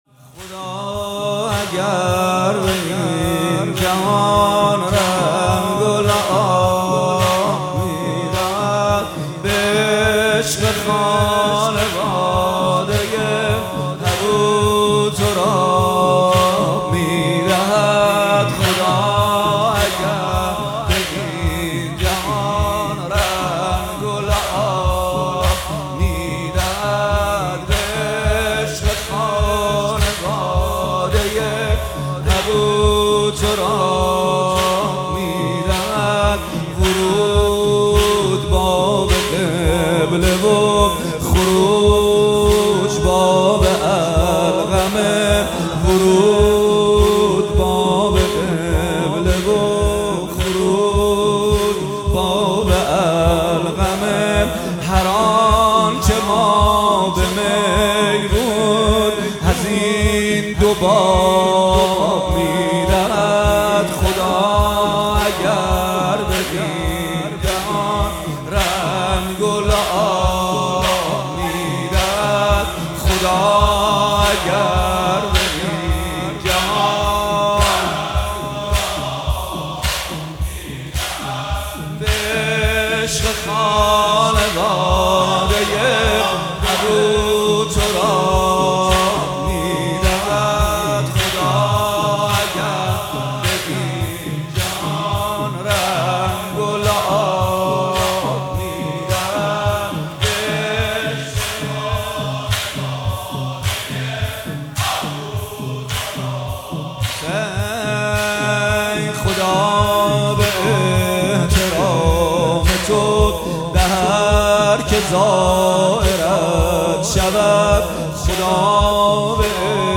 مداحی به سبک زمینه اجرا شده است.